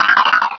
pokeemerald / sound / direct_sound_samples / cries / baltoy.aif